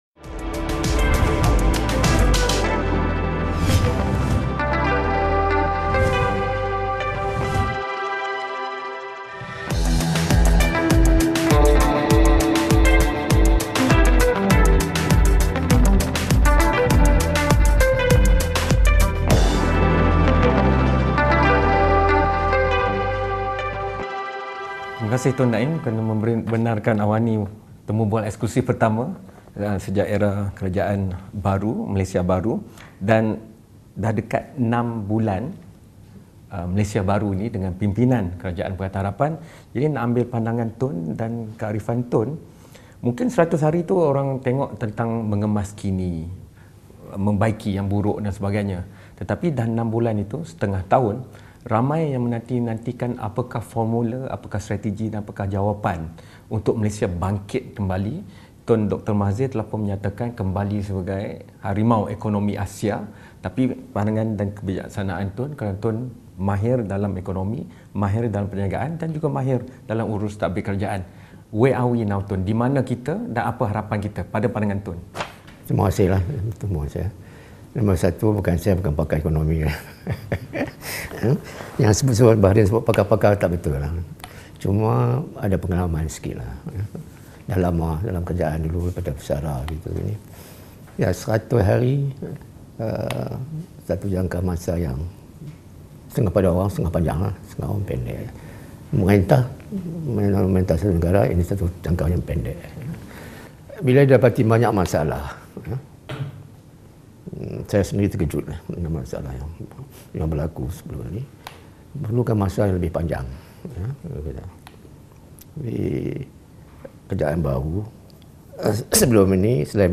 Bual bicara eksklusif bersama bekas Pengerusi Majlis Penasihat Kerajaan, Tun Daim Zainuddin. Daim tidak diam daripada menyuarakan pandangannya mengenai isu semasa.